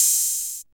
Index of /90_sSampleCDs/Roland - Rhythm Section/DRM_Drum Machine/DRM_Cheese menus